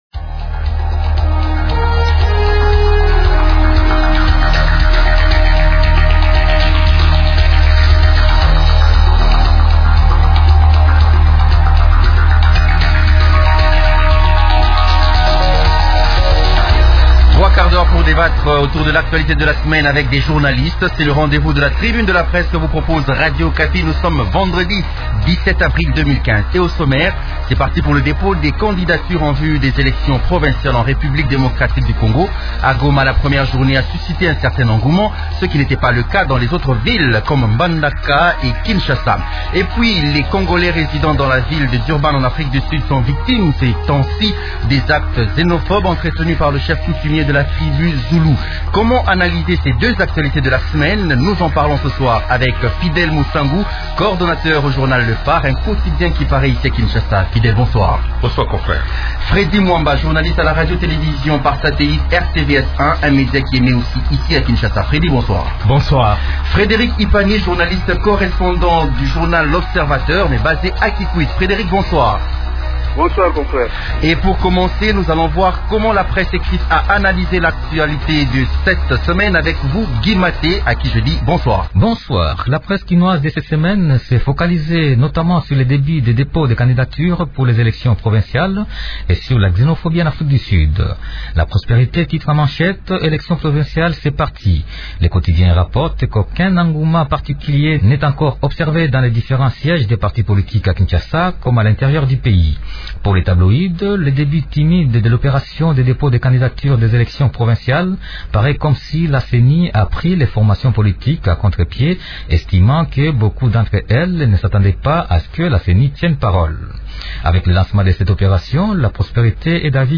Deux thèmes ont été débattus au cours de l’émission “Tribune de presse” du vendredi 17 avril: